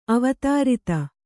♪ avatārita